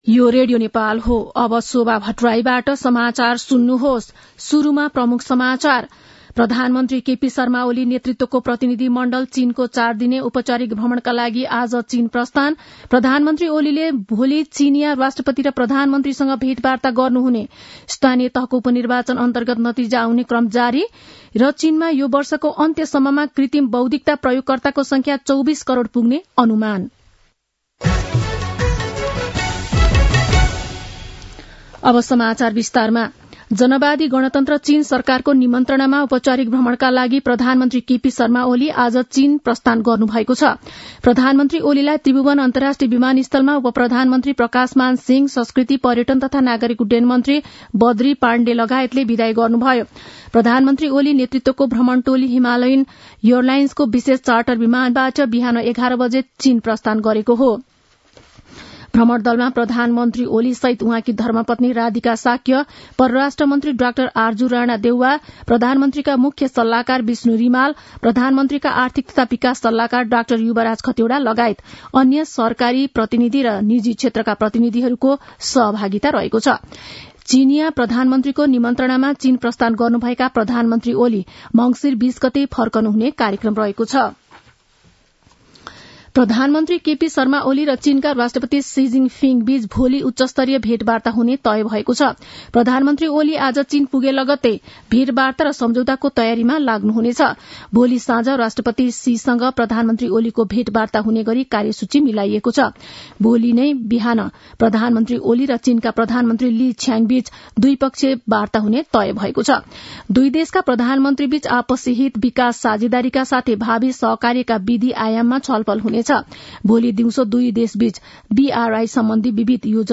दिउँसो ३ बजेको नेपाली समाचार : १८ मंसिर , २०८१
3-pm-nepali-news-1-1.mp3